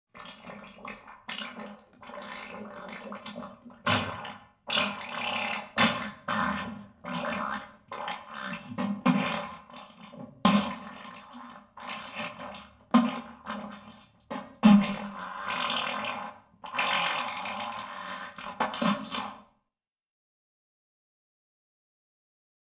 Diarrhea: Funny Wet Splat Sounds with Reverb.